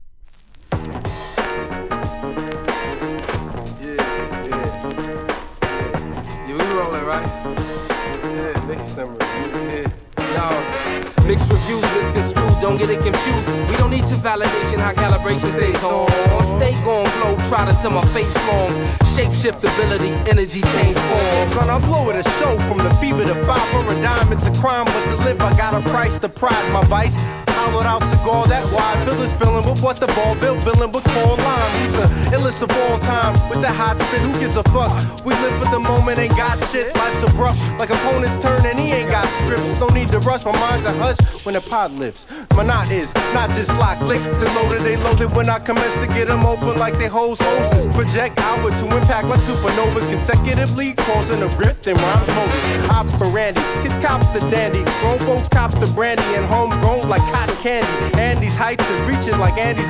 HIP HOP/R&B
ホーンが入るファンキーナンバー！